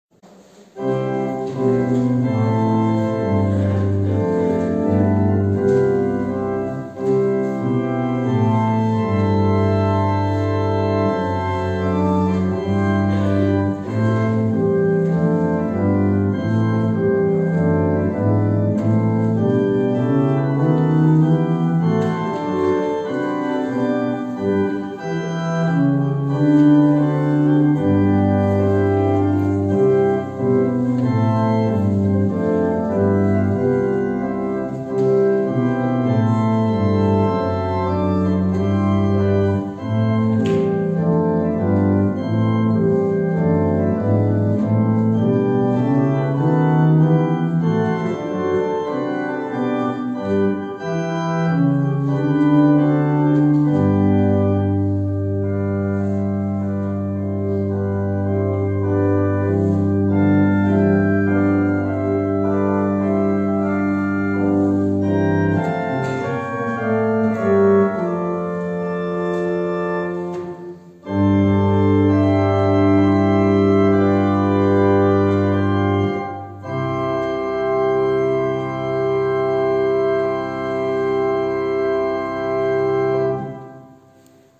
Gottesdienst mit Abendmahl zum Ostersonntag
aus der reformierten Erlöserkirche,